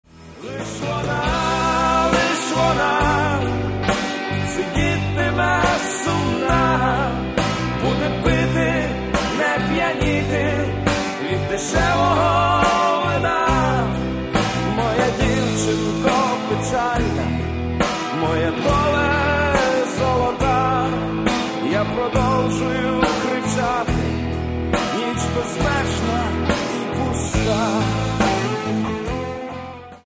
• Качество: 128, Stereo
красивые
спокойные
украинский рок